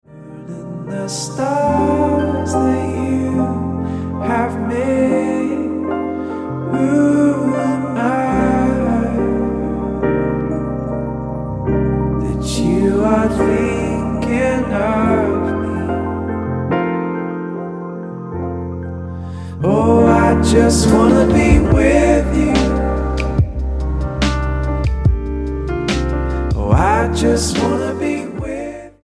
STYLE: Ambient/Meditational
keyboard